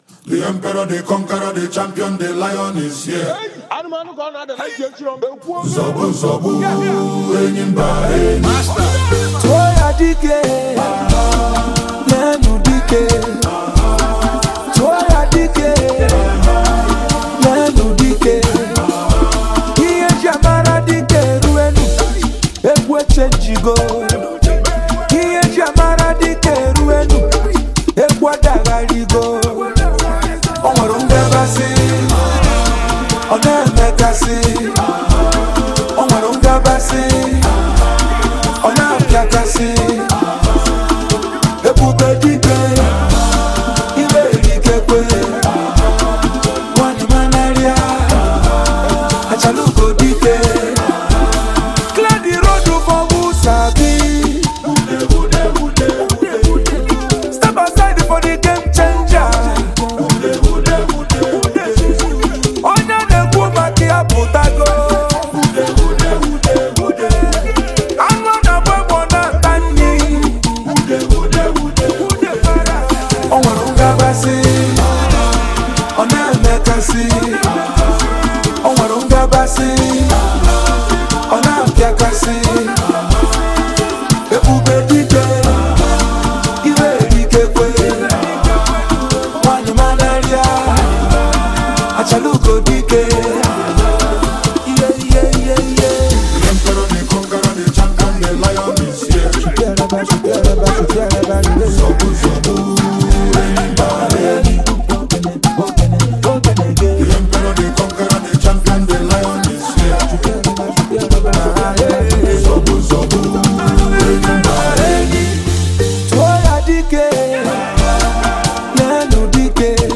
Talented Nigeria songwriter and singer